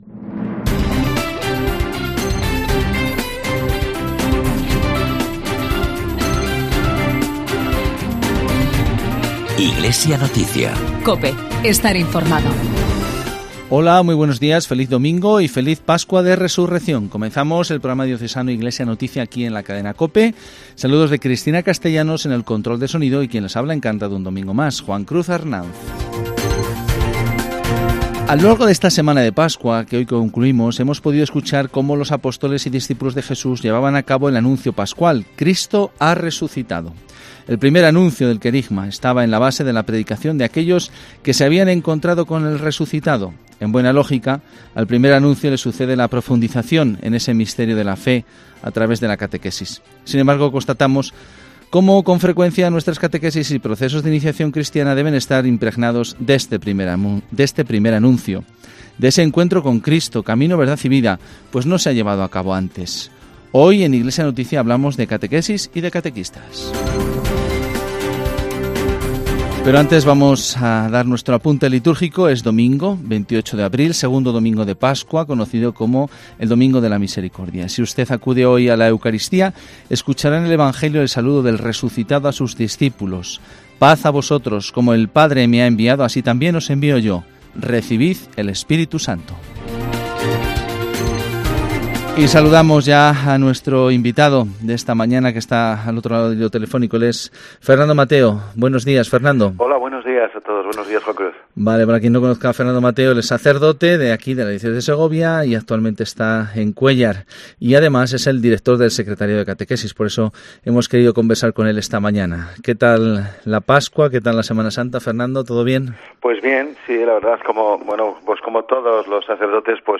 Programa semanal de información cristiana